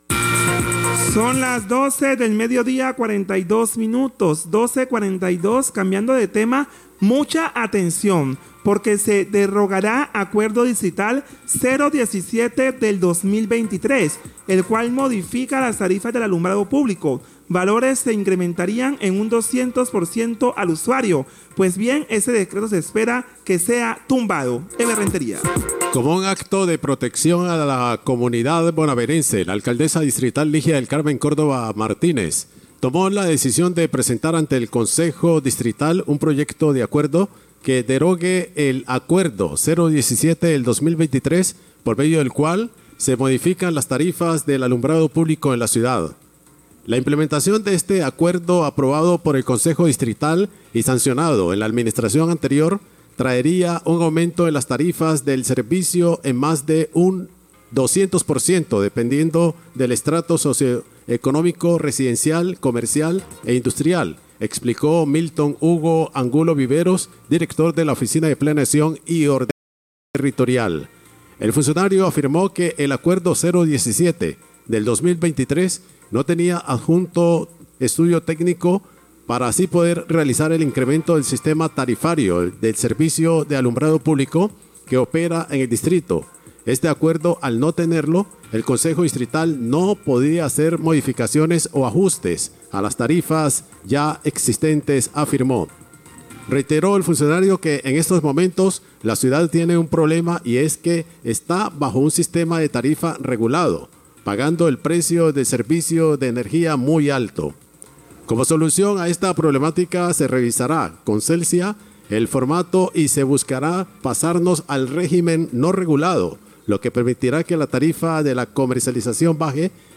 Radio
El director de la Oficina de Planeación de Buenaventura, Milton Angulo, habla de la derogación del proyecto de acuerdo que cambiaría las tarifas de alumbrado público.